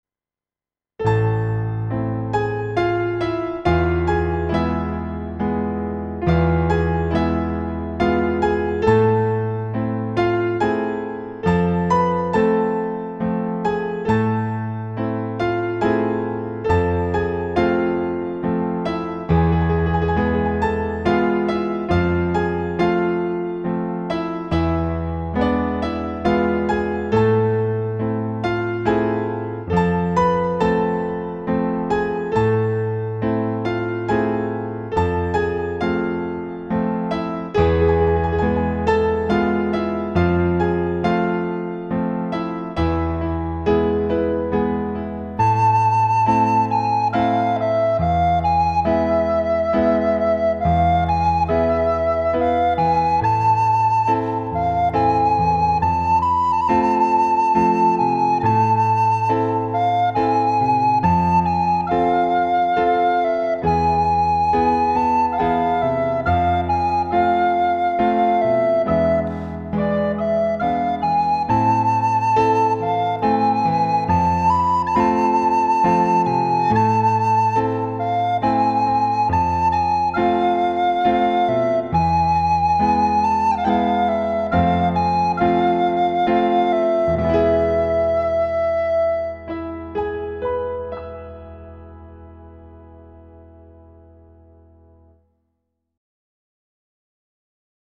ایرانی